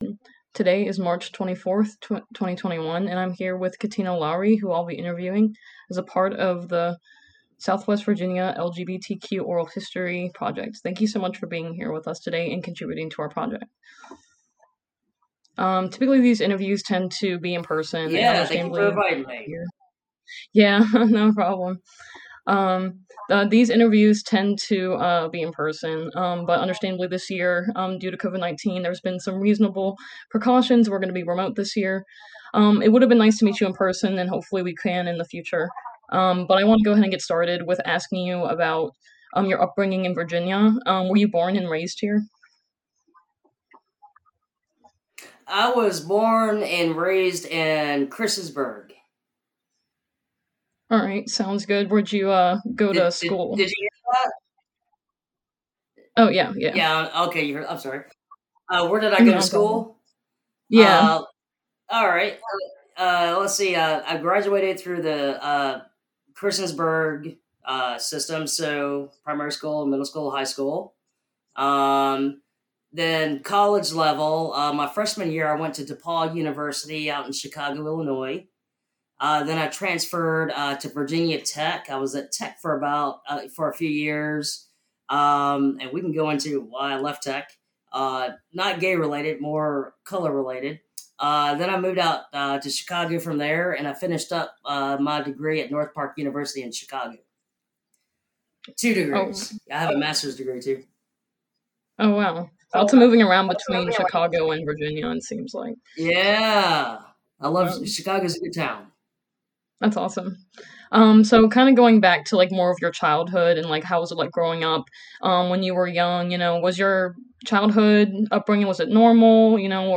Oral History Interview
Location: Online via Zencastr